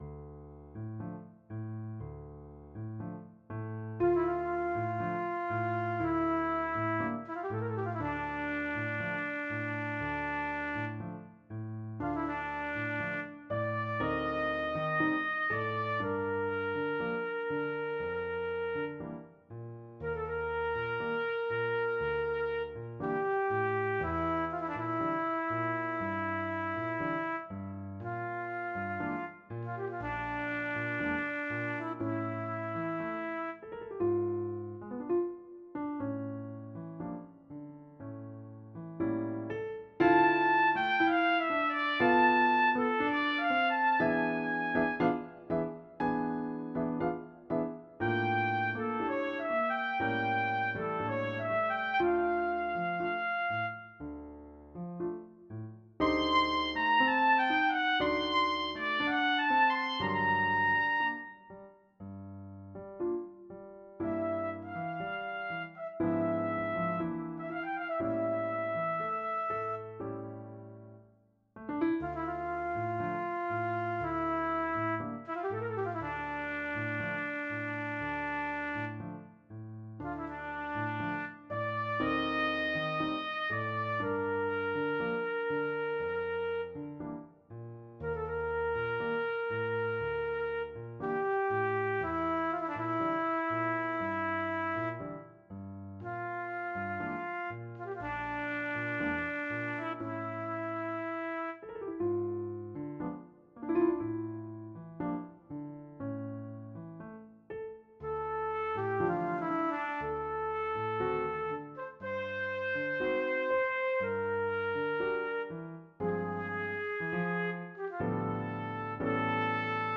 Crossroad, A Habanera for Trumpet and Piano